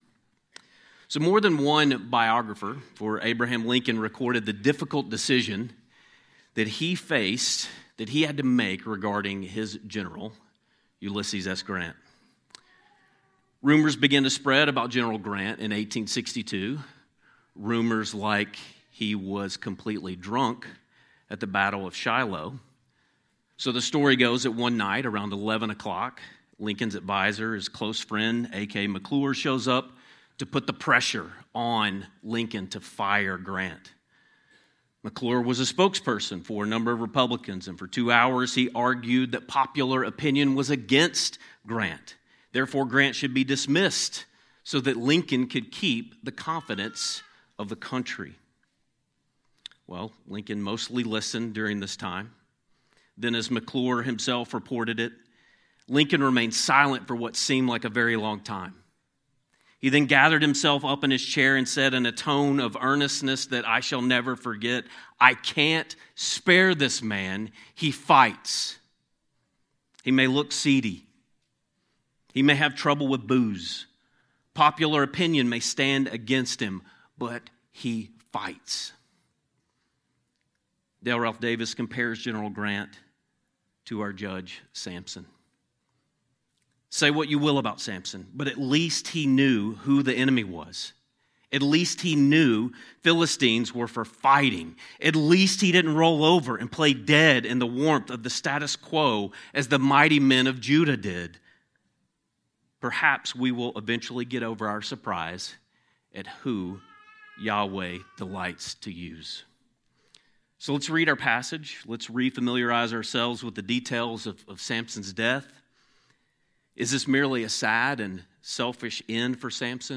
In this sermon on Judges 16:23-31, we follow Samson to his lowest moment—blinded, shackled, and mocked by his enemies—and watch as God turns apparent defeat into decisive triumph. Even though Samson’s life was marked by pride and poor choices, his final desperate prayer reveals a humbled heart that knows where true strength comes from.